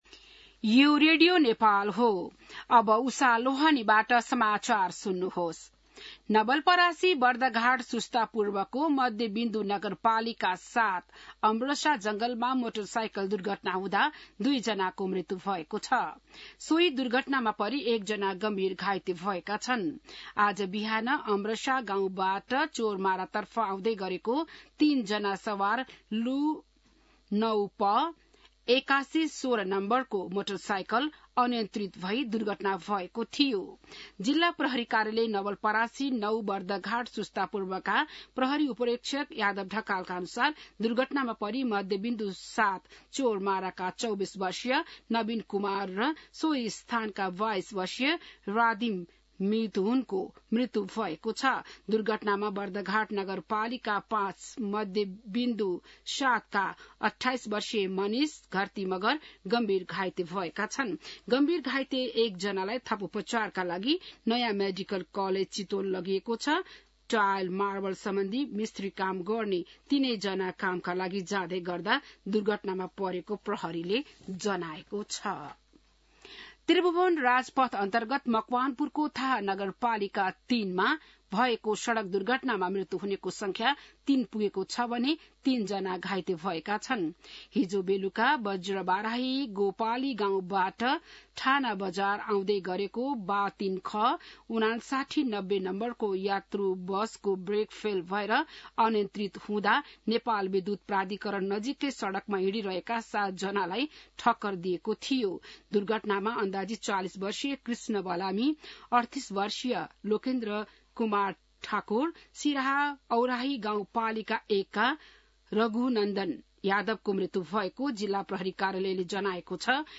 बिहान १० बजेको नेपाली समाचार : १३ जेठ , २०८२